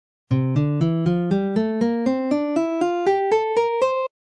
Mixolydian is the only mode that has a dominant tonality and is essentially a major scale with a minor seventh interval.
Notation formula (key of C) C-D-E-F-G-A-B-C/1
C Mixolydian scale pattern #4
c-mixolydian-mode-scale-pattern-4.mp3